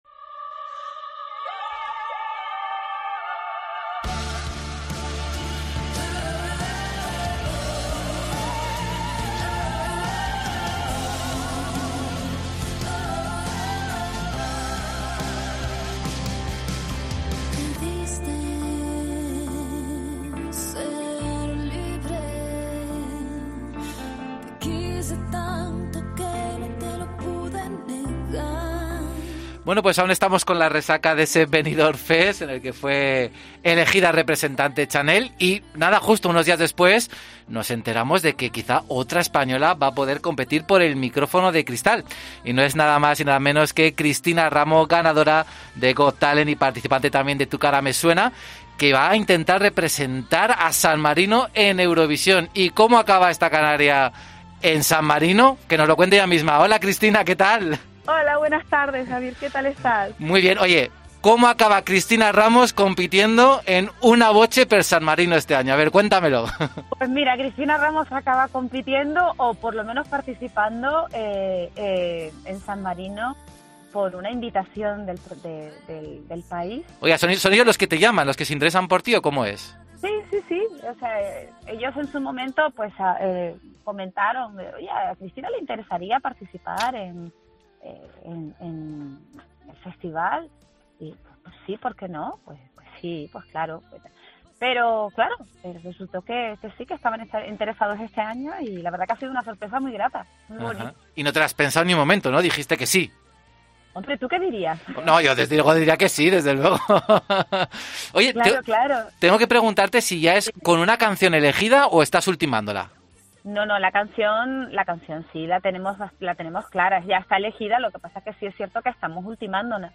Pasaporte a Eurovisión